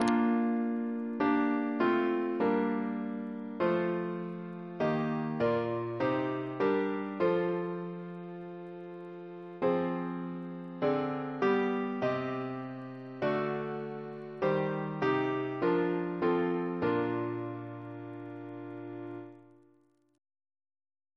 Double chant in B♭ Composer: Sir Ivor Algernon Atkins (1869-1953), Organist of Worcestor Cathedral Reference psalters: ACP: 224; RSCM: 4